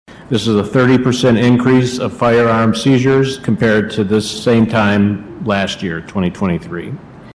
City Manager Jim Ritsema also updated the public safety department’s statistics on gun and drug seizures.